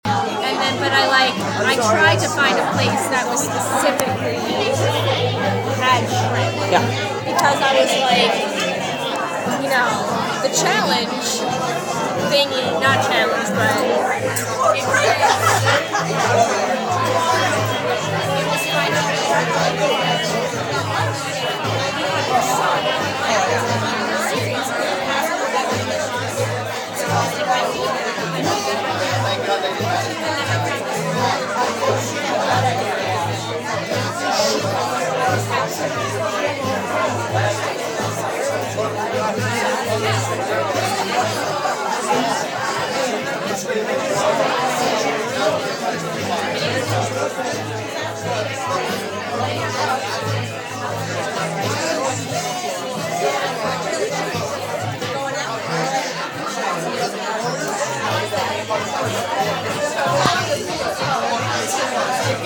On February 7th in a practice room during a rehearsal at Monroe Hall…7:15pm
Sounds: Female voices are singing in Hebrew–starts out in unison–then splits into two parts–then into three parts. At one point you can hear a shuffle of a paper.